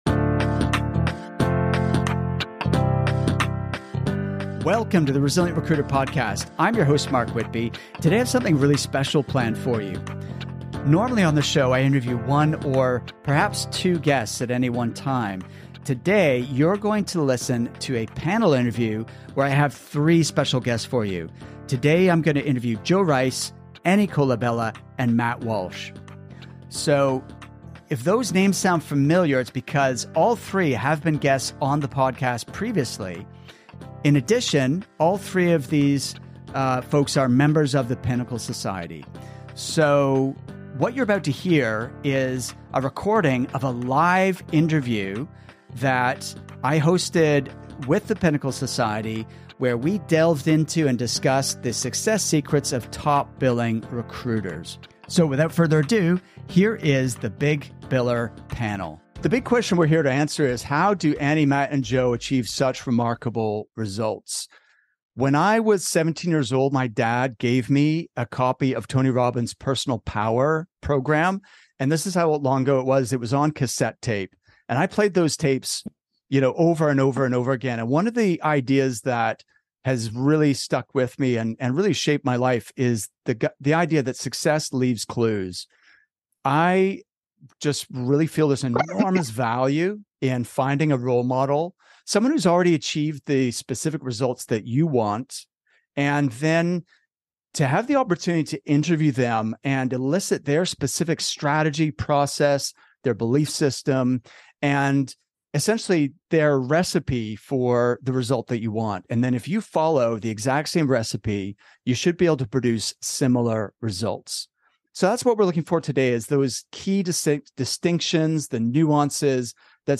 We will not only be hearing from one or two guests, but you will also hear a panel interview featuring three special guests.